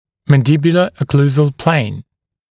[ˌmæn’dɪbjulə ə’kluːzəl pleɪn] [-səl][ˌмэн’дибйулэ э’клу:зэл плэйн] [-сэл]окклюзионная плоскость нижнечелюстной зубной дуги